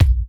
Kick_104.wav